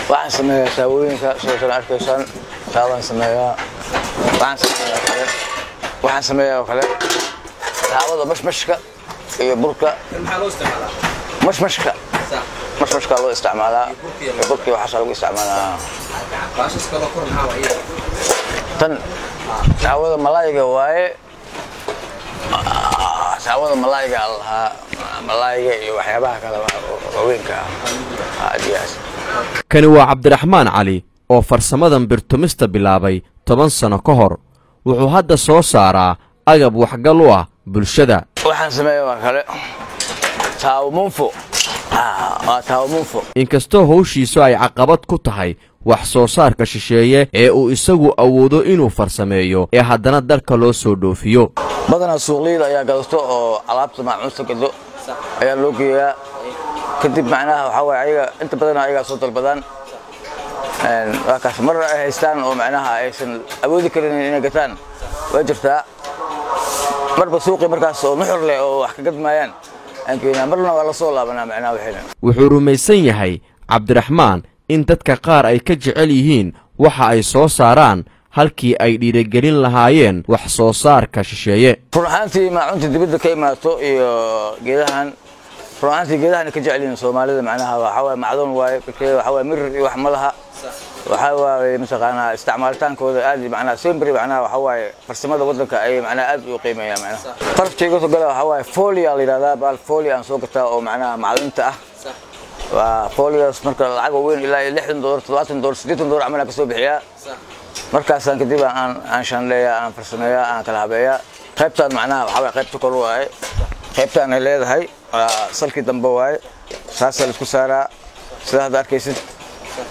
Isbeddelka Dadka ku shaqeysta Bira timidda, Warbixin, dhegeysi